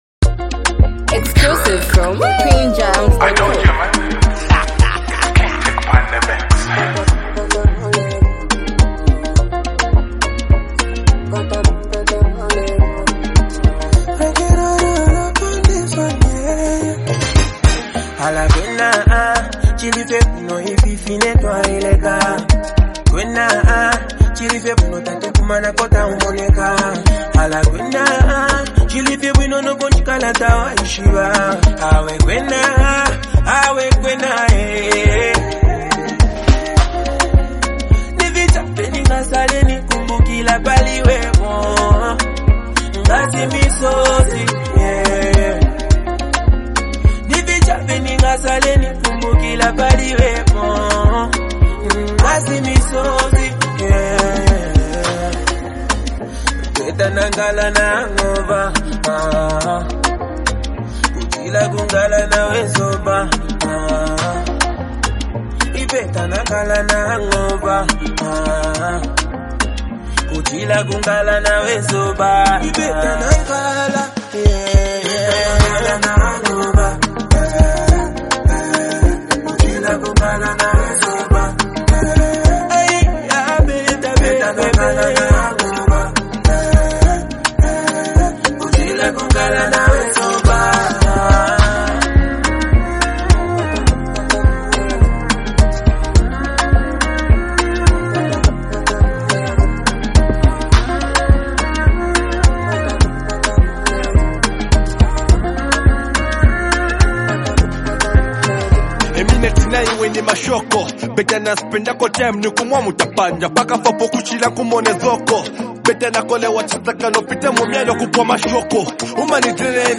a lively, feel-good song